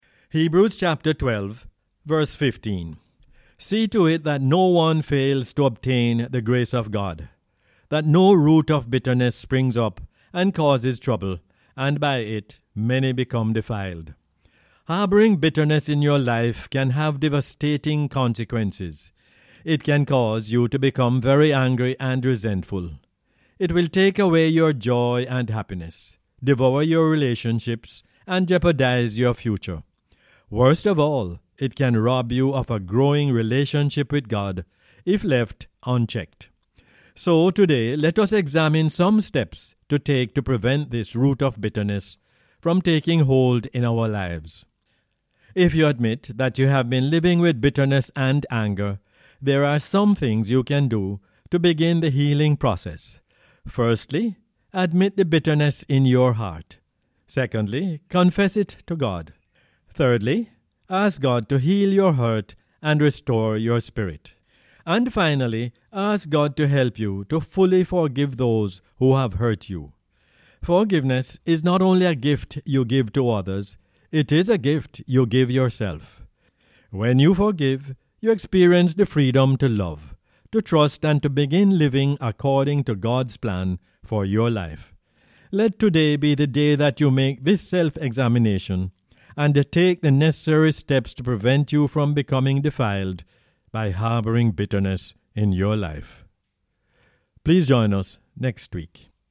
Hebrews 12:15 is the "Word For Jamaica" as aired on the radio on 30 July 2021.